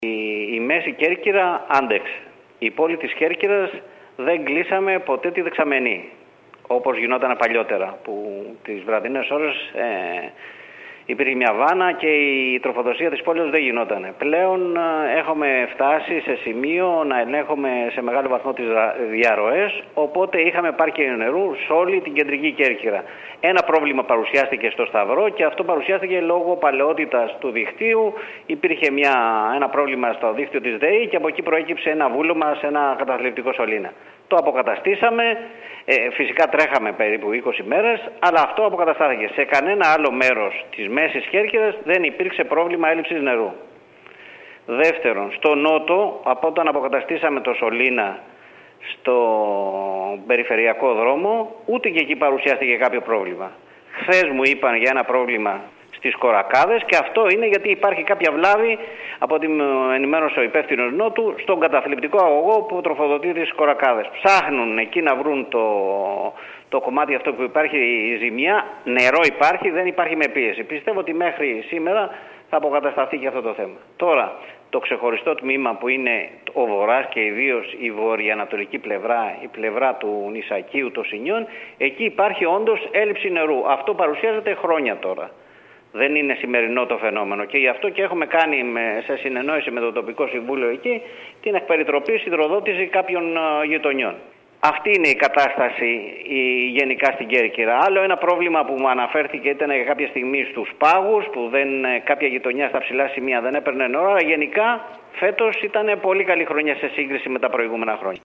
μιλώντας σήμερα στην ΕΡΤ Κέρκυρας. Τα αποθέματα νερού φέτος ήταν επαρκή και τα προβλήματα που σημειώθηκαν ήταν στο συνηθισμένο επίπεδο.